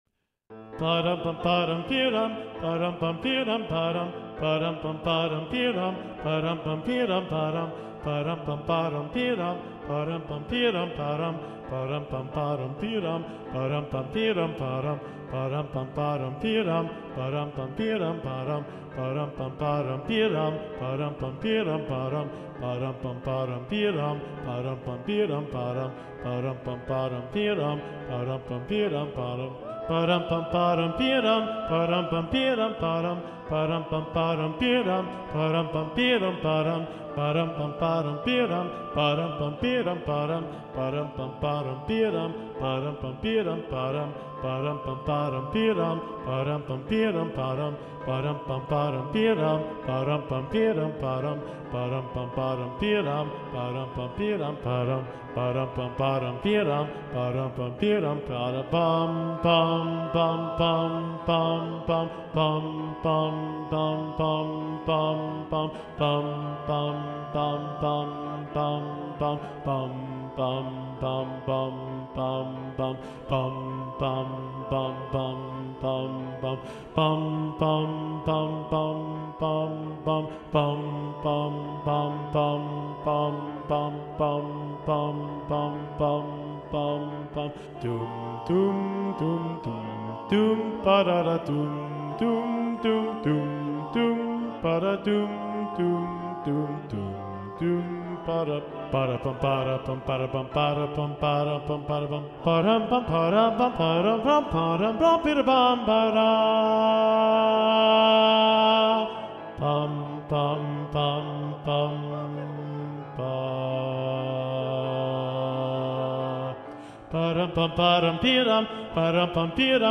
- Œuvre pour chœur à 7 voix mixtes (SSAATTB) + piano
SATB Bass (chanté)